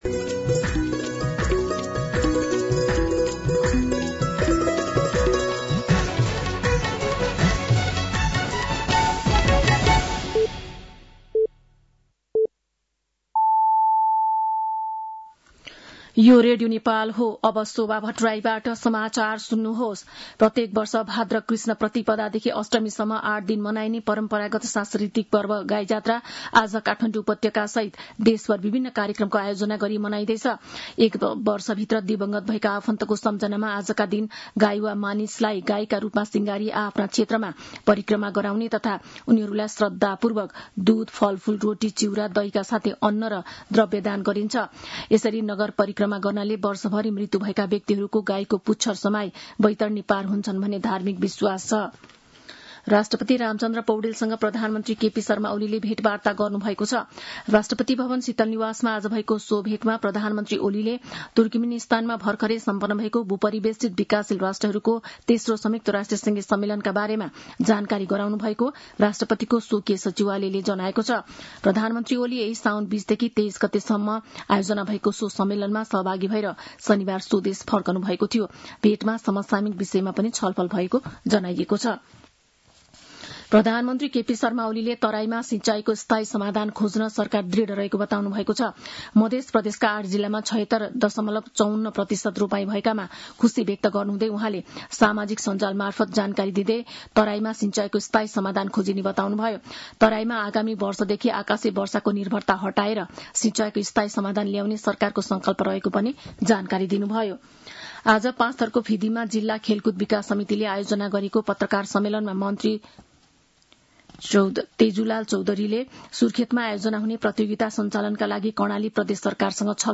साँझ ५ बजेको नेपाली समाचार : २५ साउन , २०८२
5-pm-news-4-25.mp3